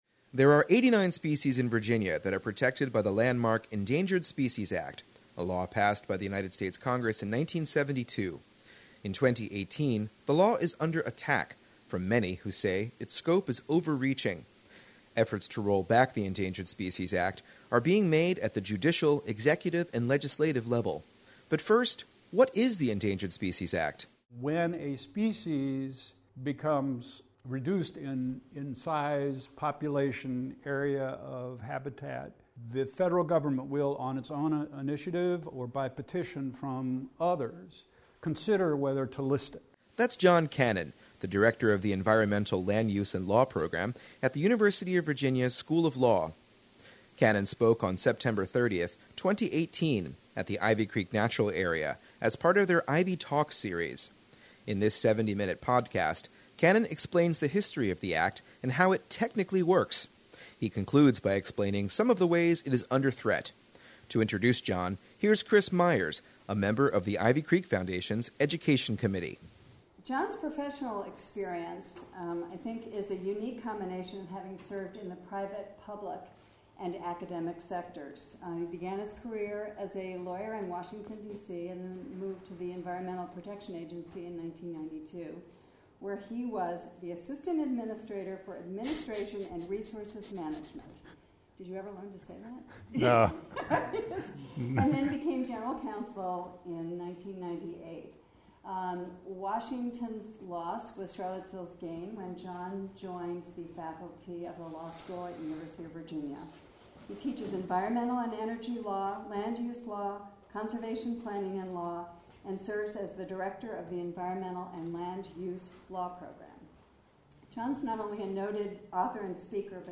in a 75 minute talk at the Ivy Creek Natural Area